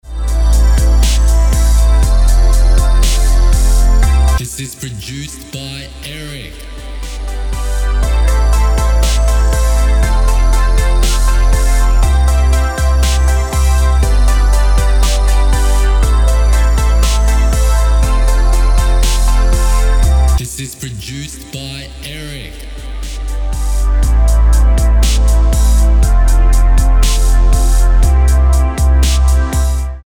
Happy, happy, happy.
Key: C Major Tempo: 120BPM Time: 4/4 Length: 3:12